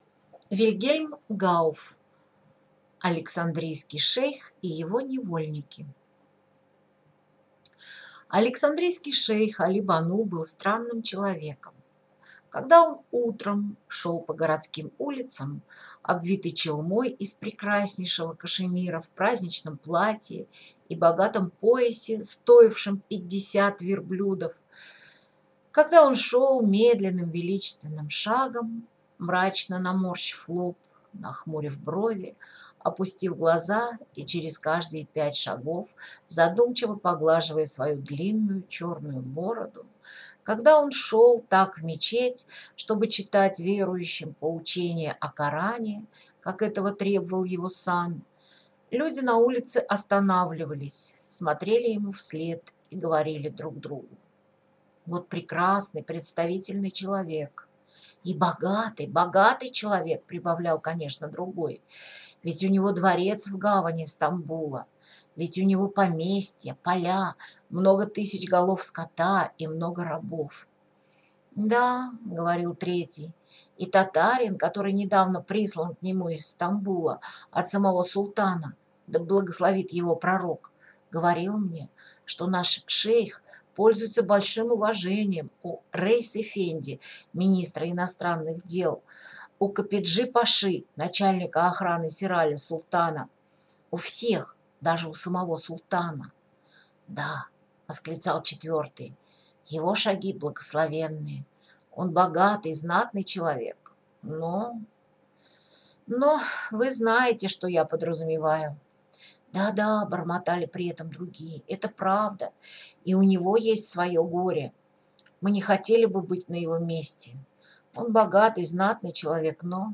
Аудиокнига Александрийский шейх и его невольники | Библиотека аудиокниг